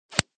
th_sfx_snap_1.ogg